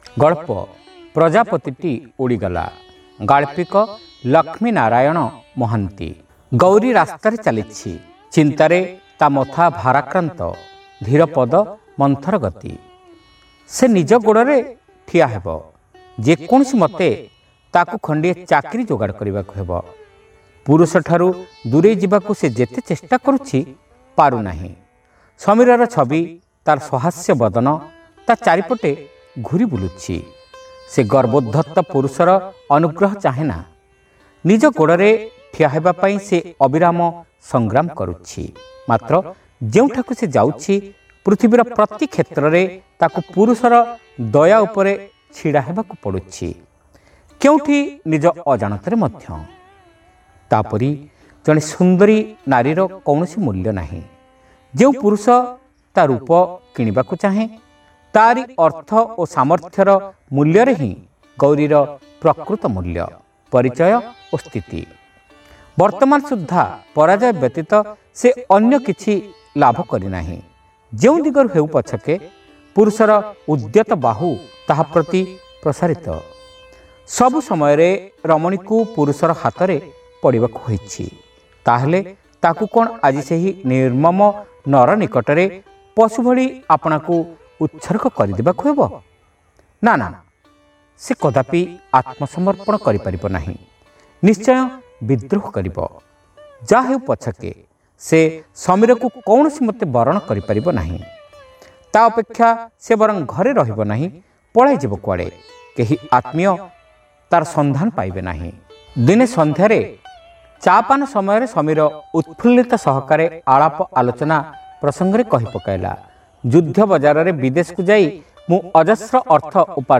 ଶ୍ରାବ୍ୟ ଗଳ୍ପ : ପ୍ରଜାପତିଟି ଉଡ଼ିଗଲା